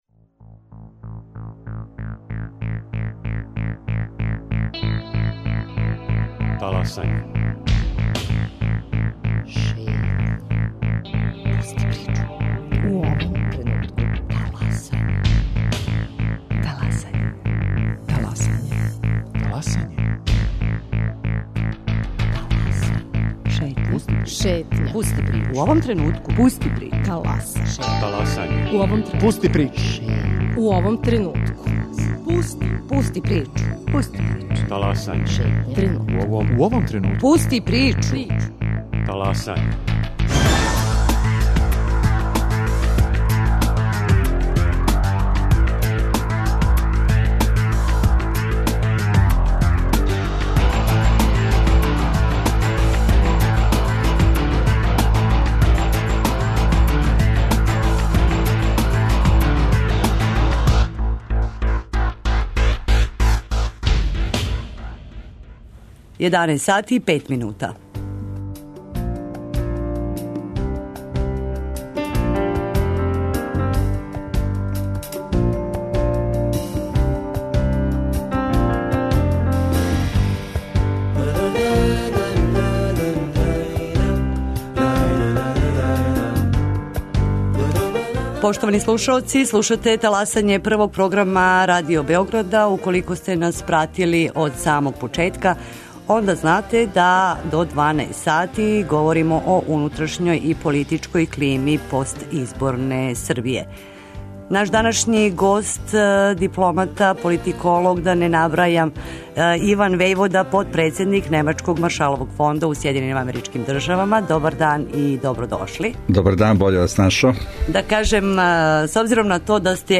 говори дипломата